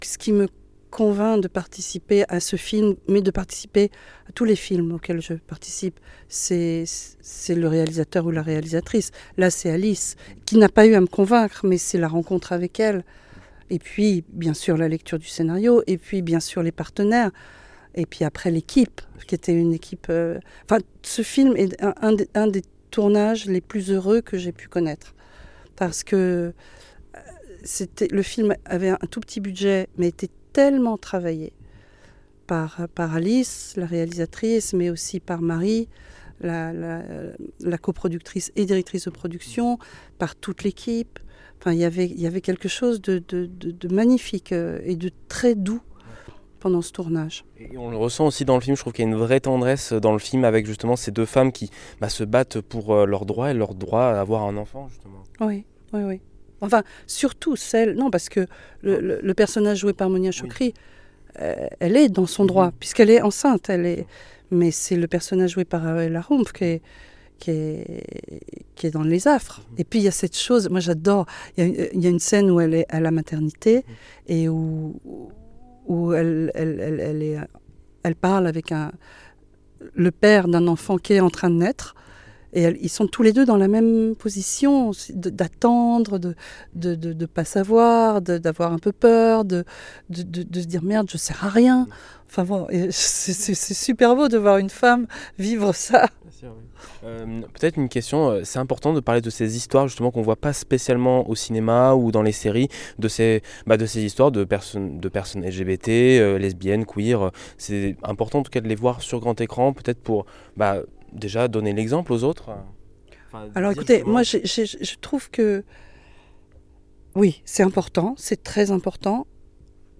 "L'un des tournages les plus heureux" : rencontre avec Noémie Lvovsky à l'affiche "Des preuves d'amour"
film cinema sortie alice-douard noemie-lvovsky des-preuves-amour comedie romantique interview entretien tournage clermont-ferrand moulins jean-carmet puy-de-dome auvergne allier